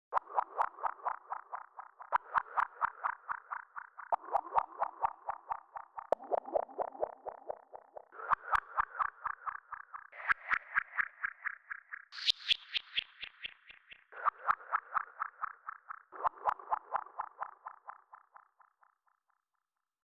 Тут три инструмента. Один пэд - обычный пилообразные стринги. Второй - арпеджиатор с фильтром.
А есть ещё третий звук, булькающий такой, как бы ещё и немного диссонирующий.